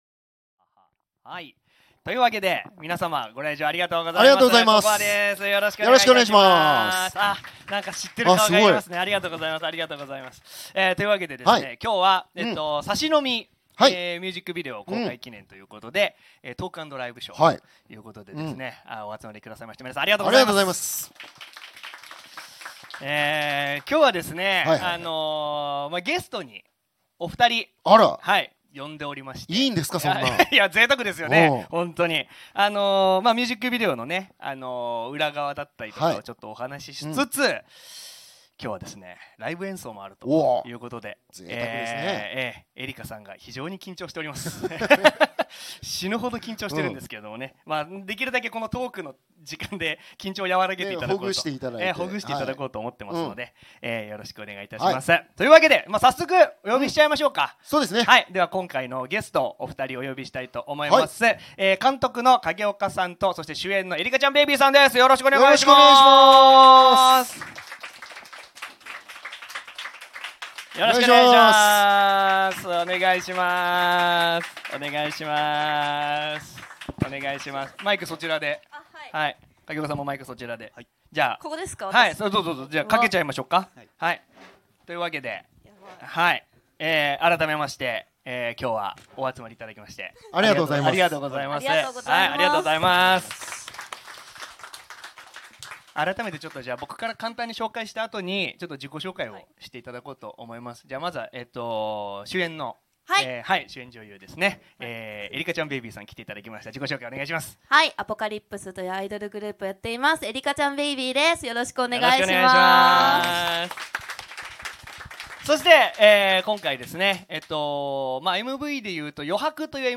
#32 サシ飲みMV撮影裏話。(先日の代官山NOMADトークイベント公開！)